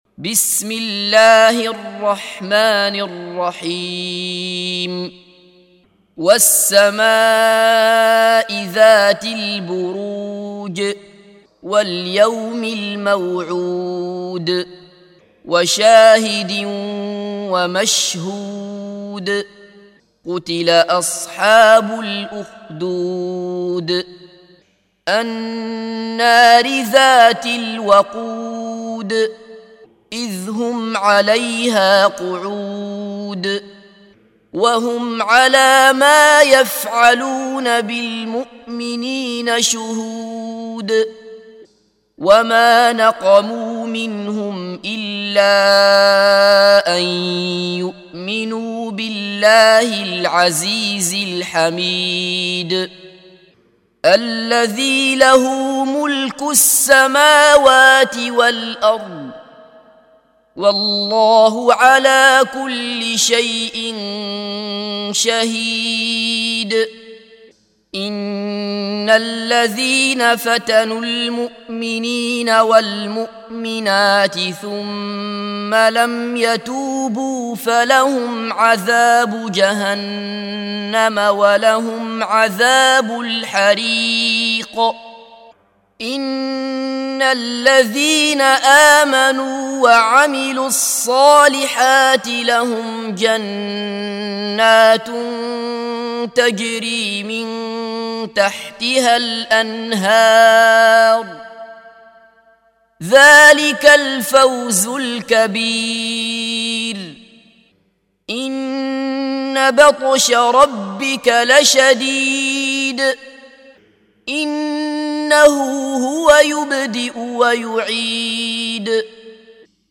سُورَةُ البُرُوجِ بصوت الشيخ عبدالله بصفر